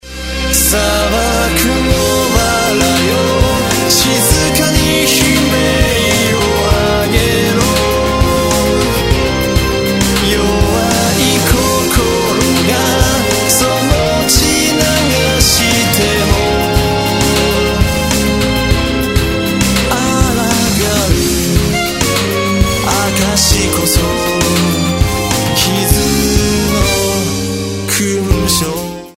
★録り下ろしキャラクターソング４曲＋各カラオケ